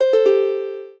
lyre_c1ag.ogg